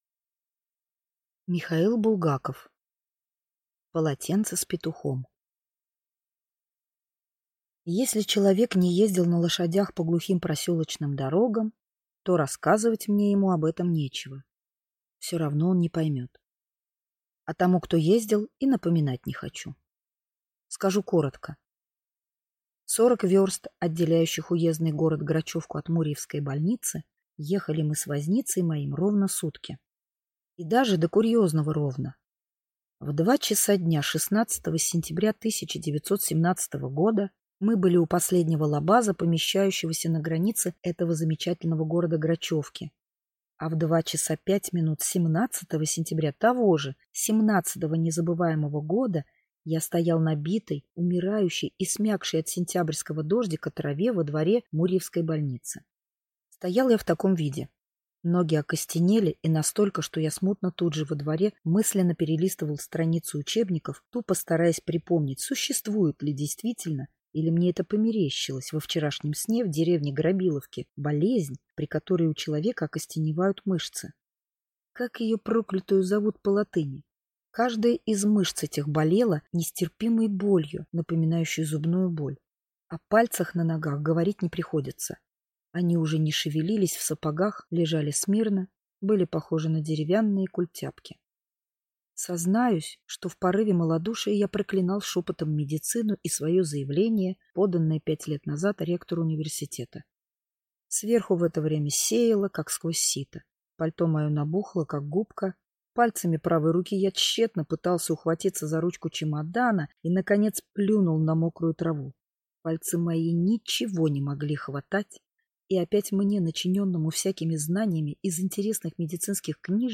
Аудиокнига Полотенце с петухом | Библиотека аудиокниг